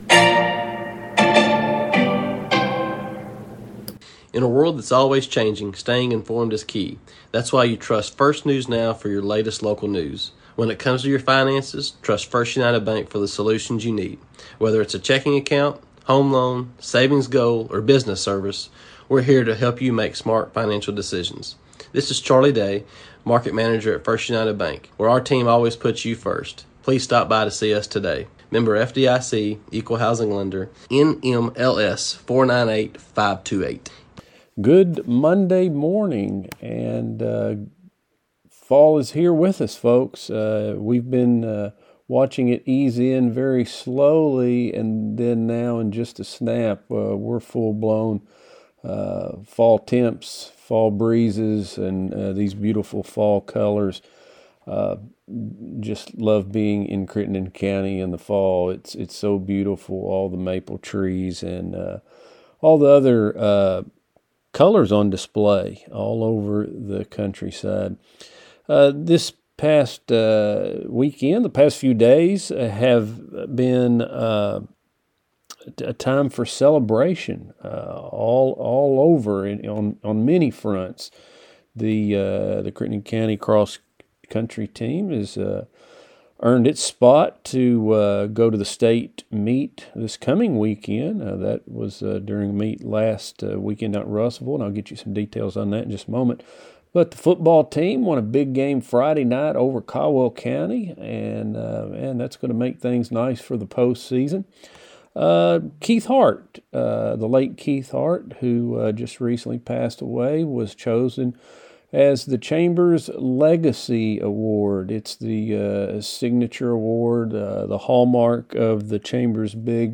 News | Sports | Interview